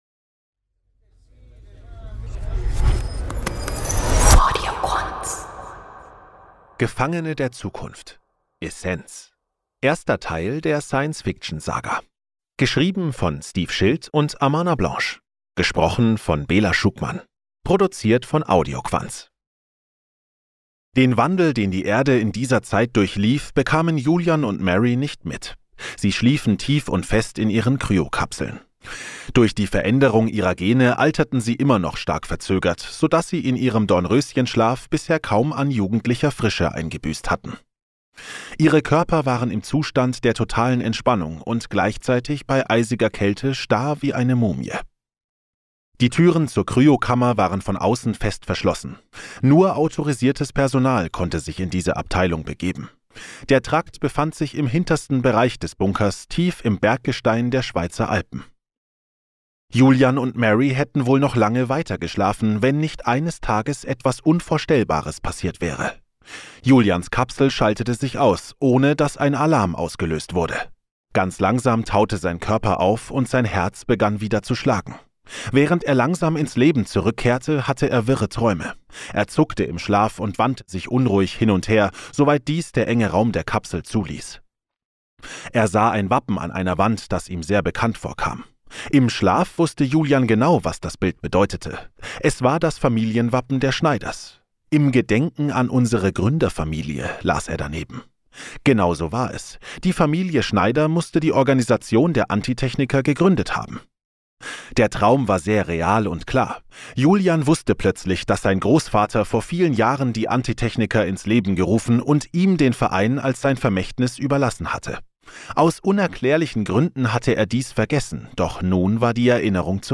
Der spannende Science Fiction Roman des renommierten Autors Steve Schild – Jetzt als Hörbuch